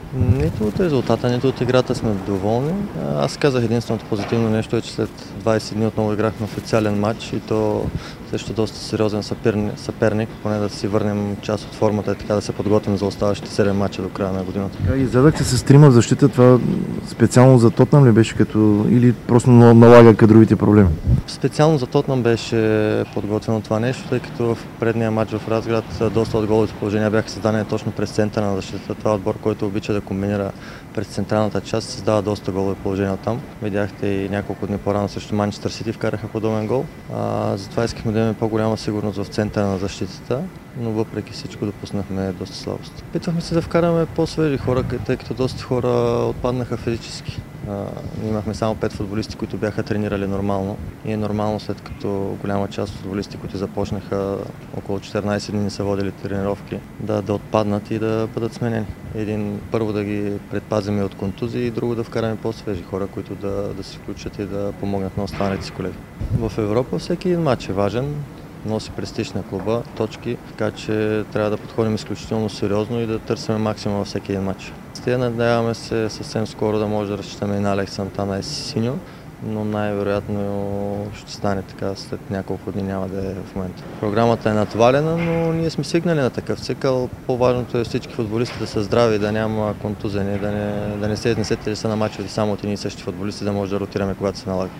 говори след пристигането на отбора на Летище Варна. Разградчани загубиха с 0:4 от Тотнъм и бяха тотално надиграни от англичаните.